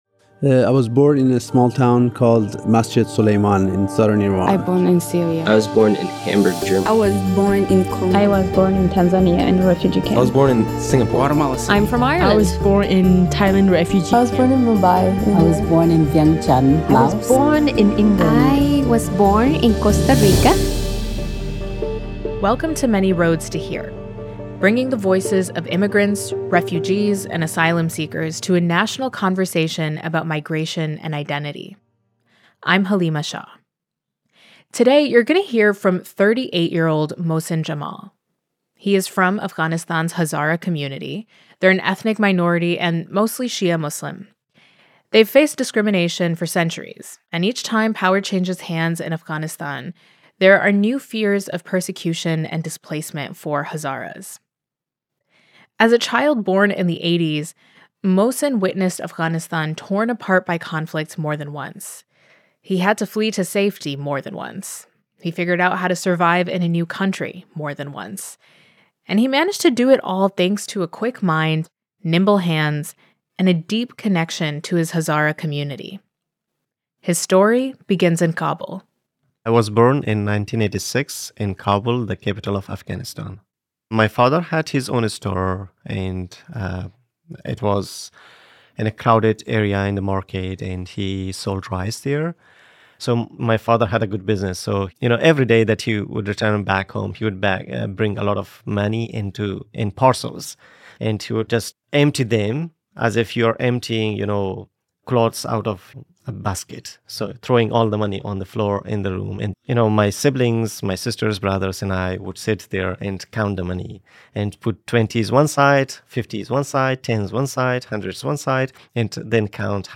Thank you to St. Andrew Lutheran Church in Beaverton for the use of their space to record the interview. This episode is a part of a series exploring the stories and experiences of Asian Americans. in a climate of Anti-Asian rhetoric and increasing violence.